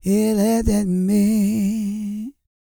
E-GOSPEL 218.wav